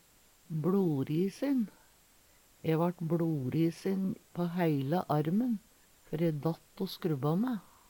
bLorisin - Numedalsmål (en-US)